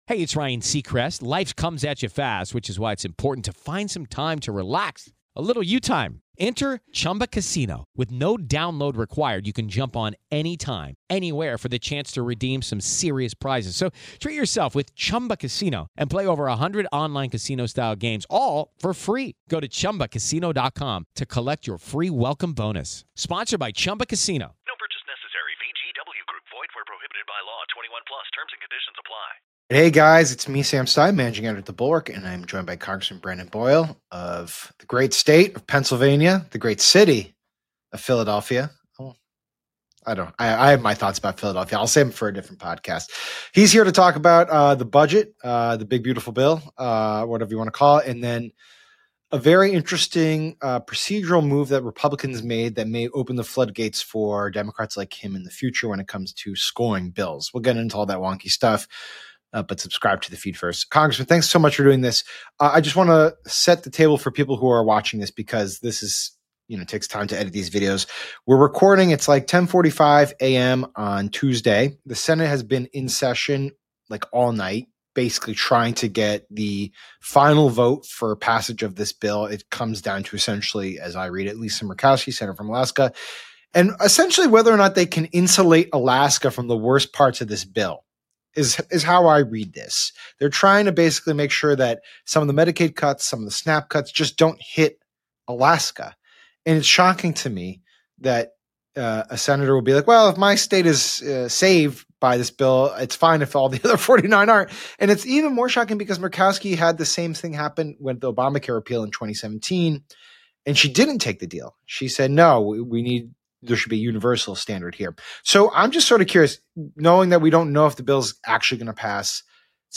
Sam Stein interviews Rep. Brendan Boyle about the GOP’s new budget maneuver, what it means for Medicaid, and how Republicans are breaking decades of precedent to push tax cuts for the wealthy.